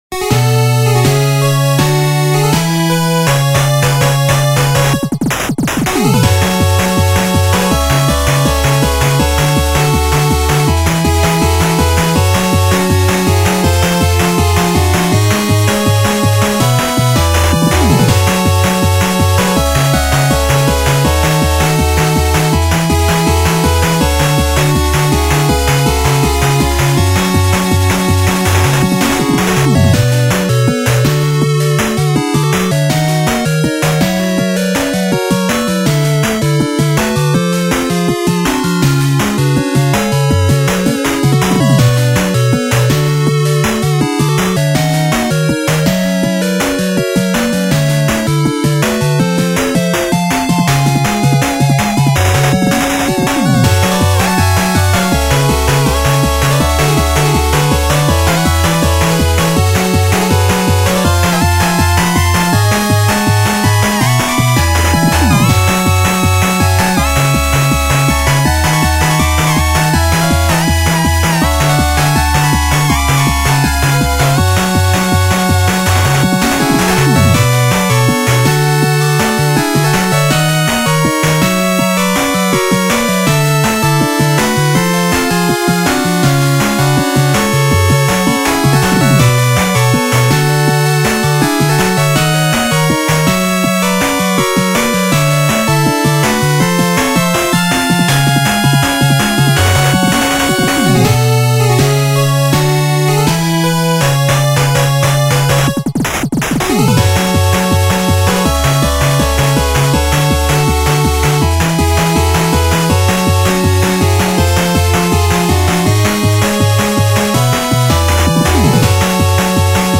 ジャンルファミコン風、8-Bit
BPM１６２
使用楽器8-Bit音源
原曲はシンプルな構成だったので、8-Bitバージョンへのアレンジも違和感ないですね。